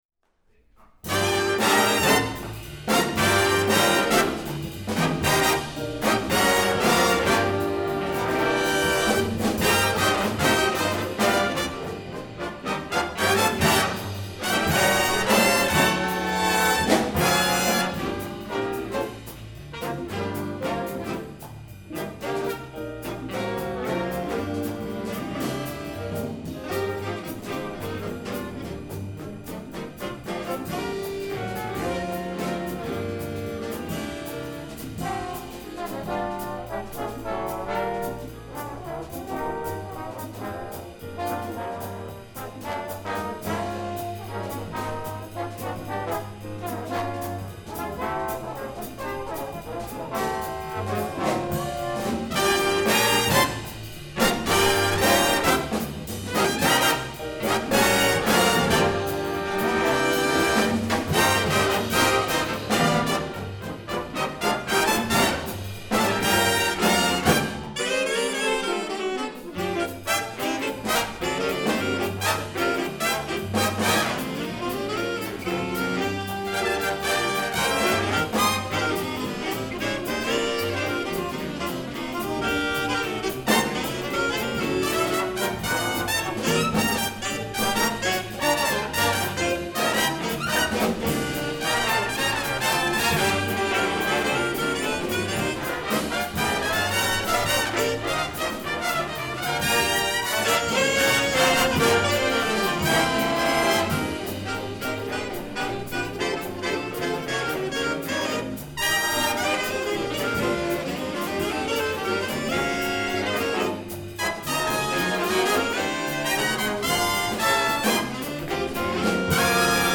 Santa Barbara City College Lunch Break Band Concert, Spring 2004